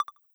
Modern UI SFX / Buttons
GenericButton7.wav